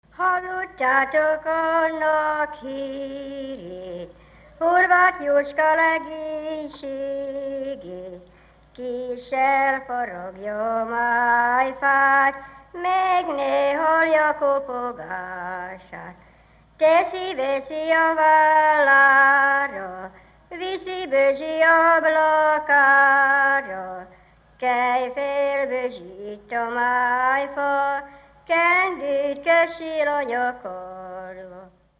Dunántúl - Zala vm. - Nova
ének
Stílus: 6. Duda-kanász mulattató stílus
Szótagszám: 8.8.8.8
Kadencia: 1 (1) 1 1